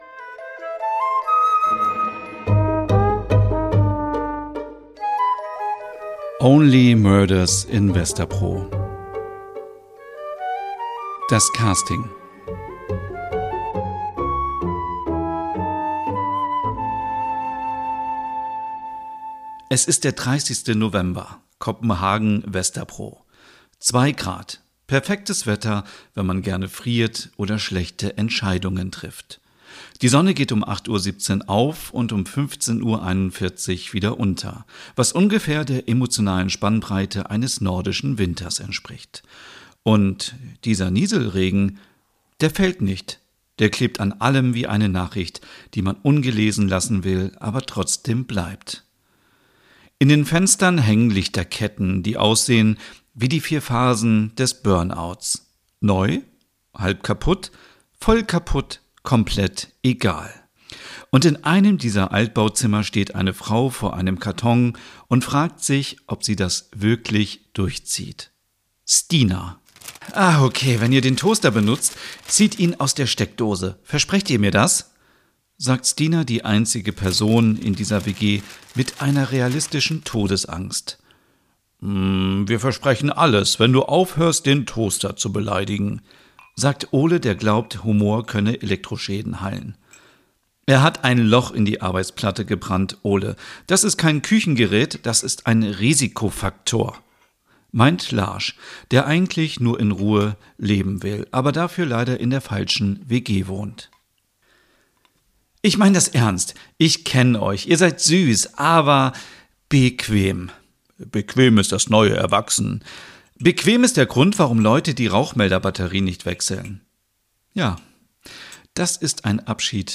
Dann entsteht Only Murders in Vesterbro: ein weihnachtliches Crime-Hörspiel voller nordischer Atmosphäre, schräger Charaktere und warmem Erzähler-Ton.